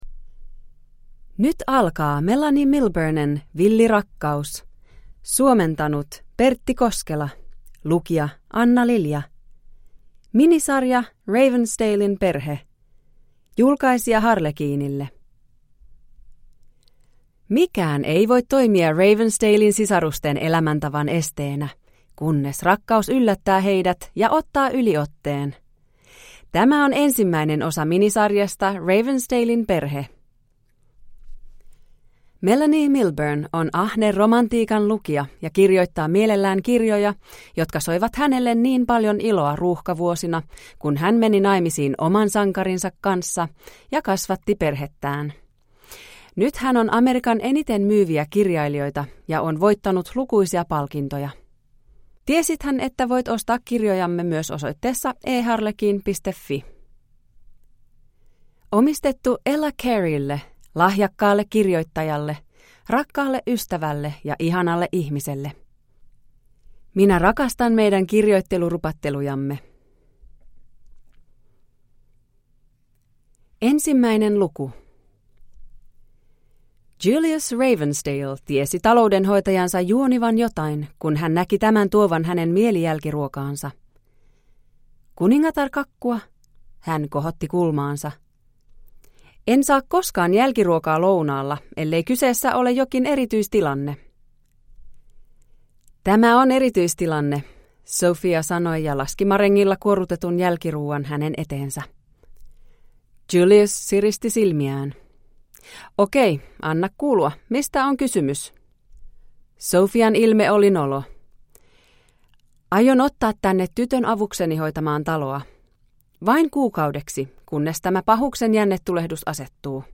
Villi rakkaus – Ljudbok